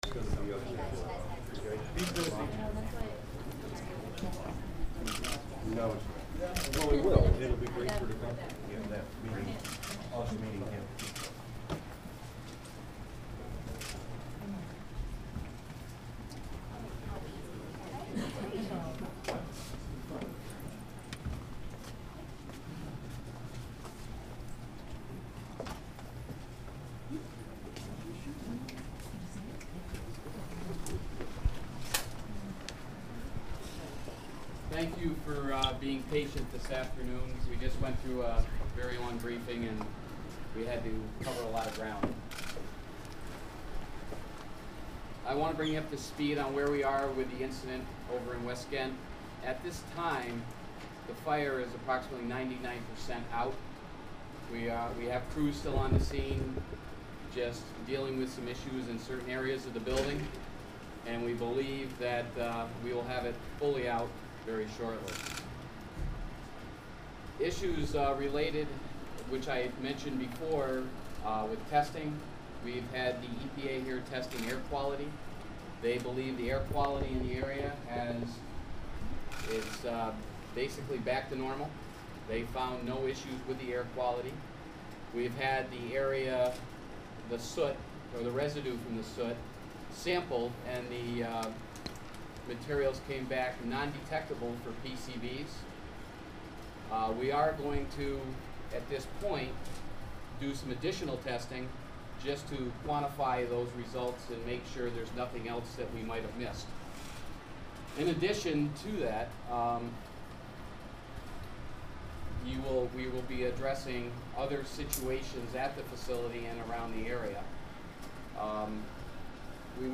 Columbia County Emergency Services press conference about TCI chemical fire. (Audio)